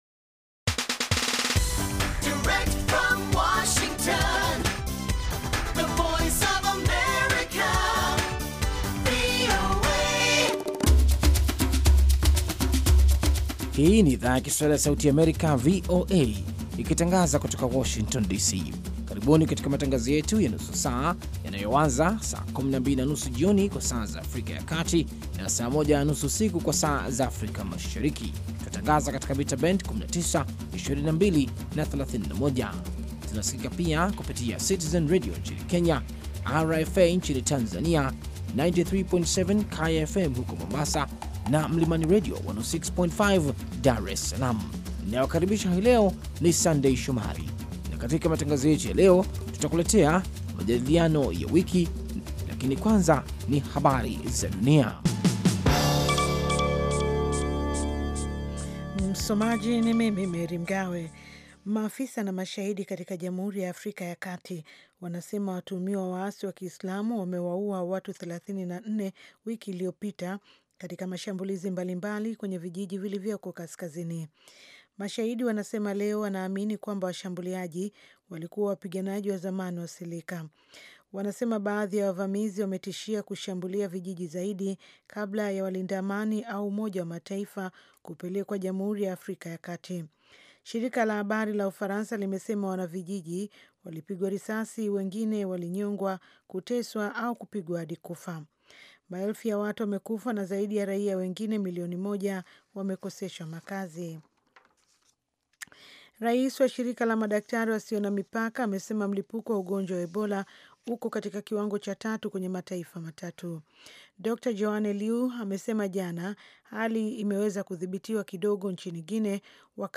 Matangazo ya saa nzima kuhusu habari za kutwa, ikiwa ni pamoja ripoti kutoka kwa waandishi wetu sehemu mbali mbali duniani na kote Afrika Mashariki na Kati, na vile vile vipindi na makala maalum kuhusu afya, wanawake, jamii na maendeleo.